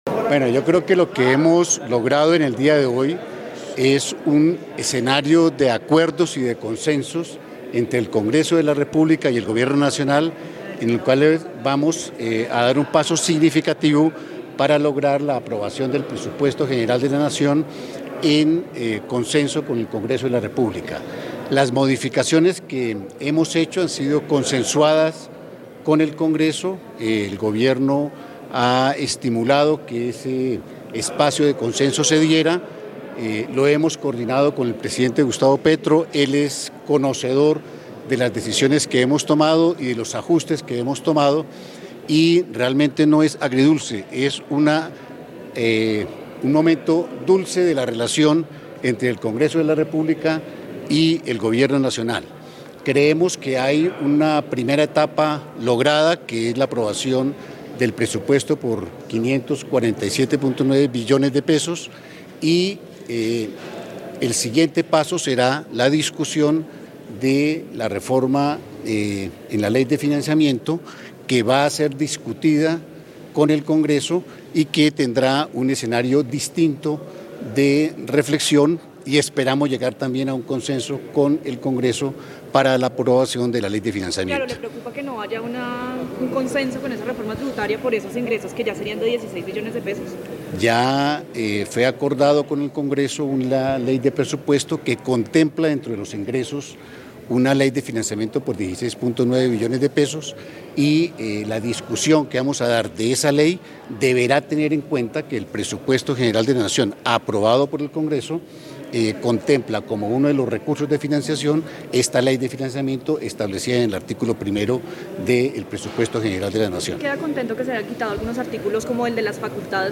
Declaración a medios de ministro de Hacienda Germán Ávila
Declaración a medios | 24 de septiembre
declaracion-a-medios-de-ministro-de-hacienda-german-avila